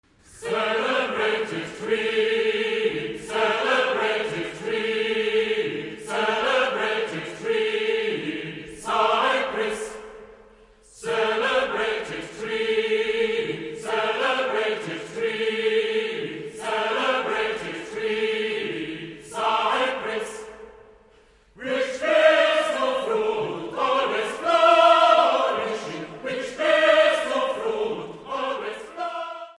a choir piece